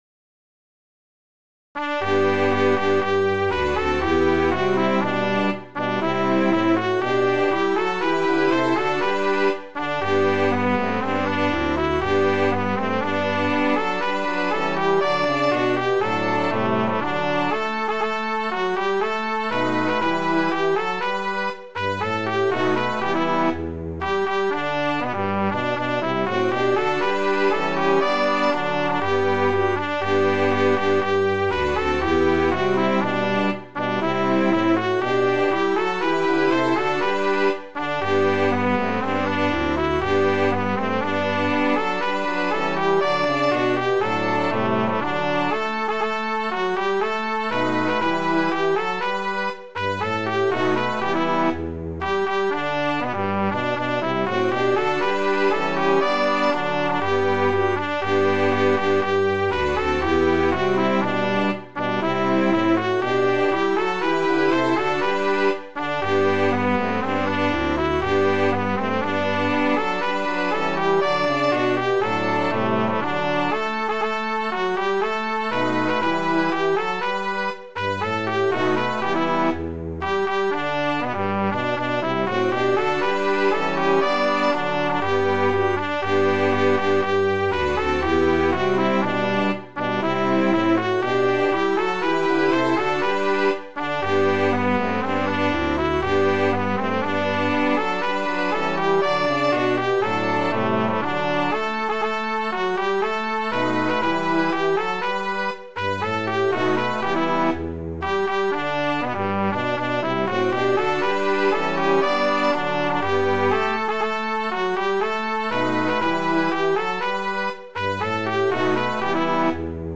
Midi File, Lyrics and Information to The Liberty Song